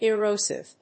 音節e・ro・sive 発音記号・読み方
/ɪróʊsɪv(米国英語), ɪrˈəʊsɪv(英国英語)/